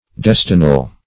Search Result for " destinal" : The Collaborative International Dictionary of English v.0.48: Destinal \Des"ti*nal\, a. Determined by destiny; fated.